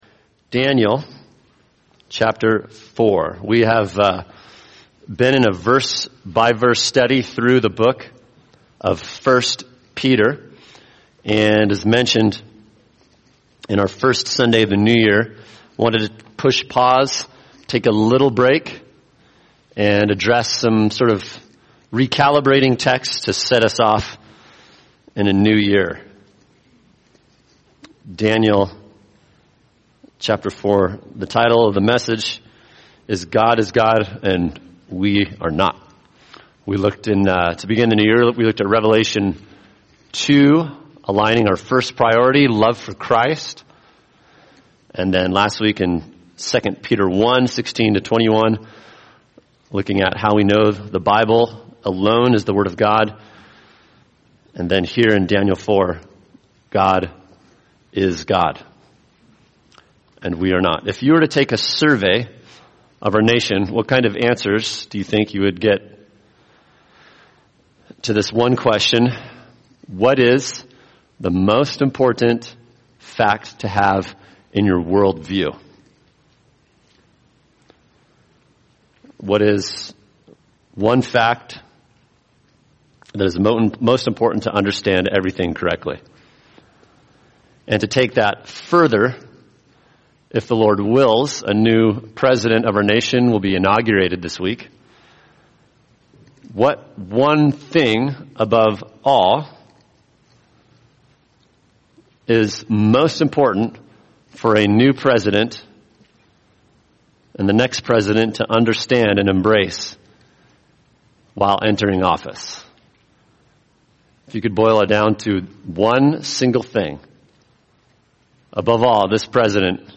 [sermon] Daniel 4 God is God and We Are Not | Cornerstone Church - Jackson Hole